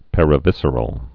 (pĕrə-vĭsər-əl)